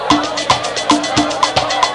Caribbean Intro Sound Effect
Download a high-quality caribbean intro sound effect.
caribbean-intro.mp3